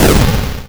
bakuhatu22.wav